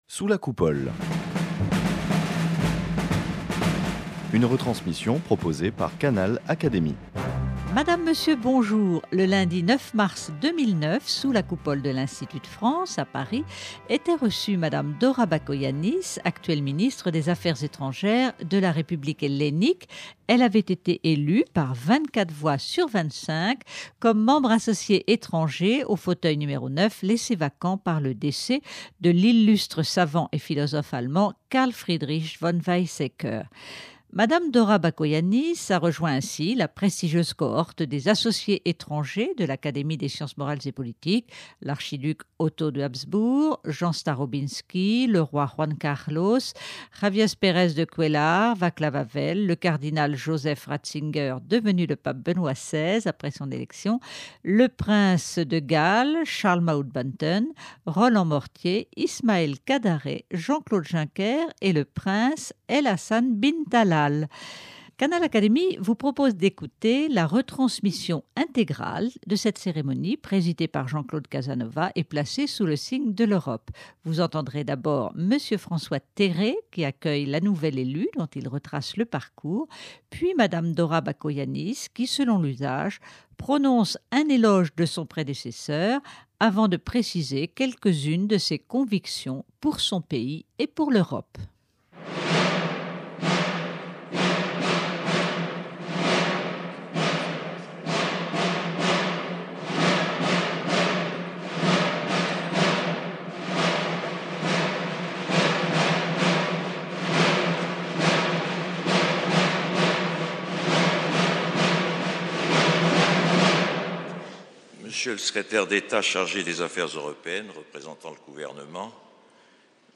Dora Bakoyannis, élue membre associé étranger à l’Académie des sciences morales et politiques, en mai 2008, a été reçue sous la Coupole en présence de nombreuses personnalités grecques et françaises le lundi 9 mars 2009.
Ce sont ces deux discours que vous pouvez successivement écouter dans cette émission.